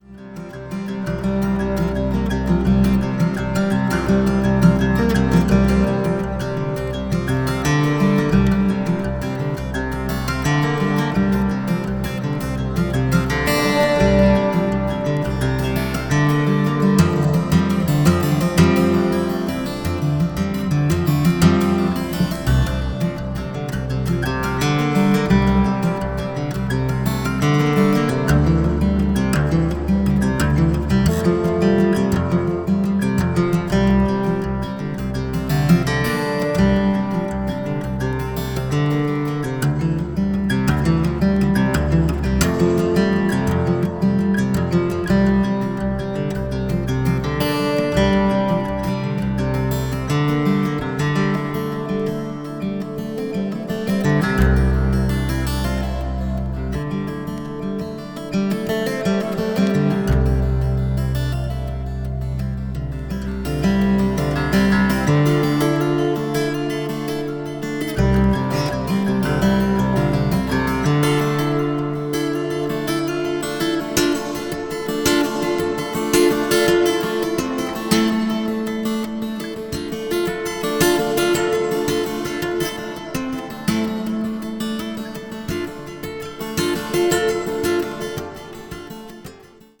奥行きのある立体的な音響も最高です。